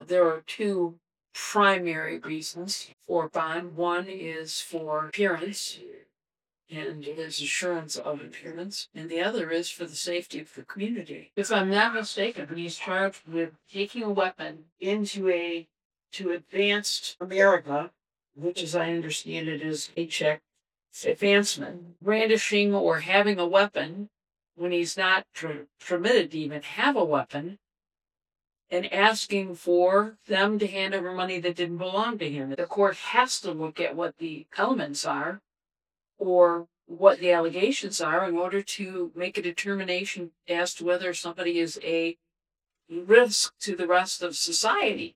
Judge Laura Schaedler explained the legal standards governing bond decisions while addressing arguments from the defense seeking a substantial reduction.
That was Judge Laura Schaedler.
judge-schaedler-safety-concerns.mp3